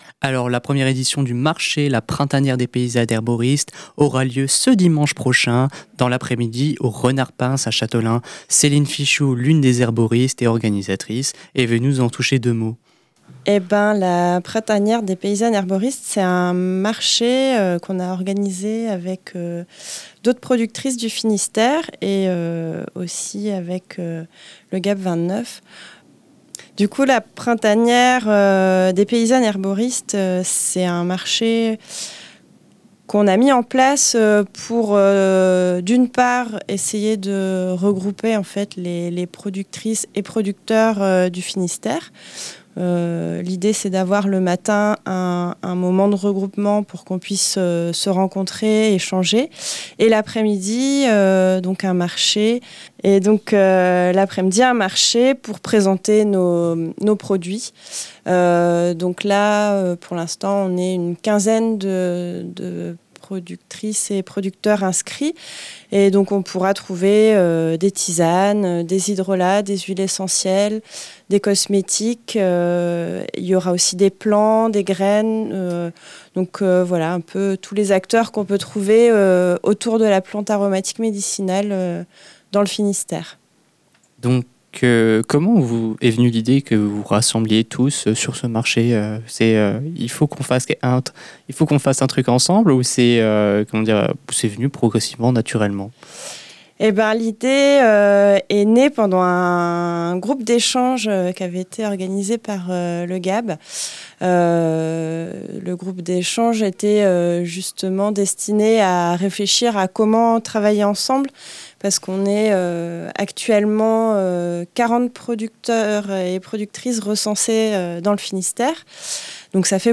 LEMRUB-Printaniere-des-paysannes-herboristes-Chateaulin.mp3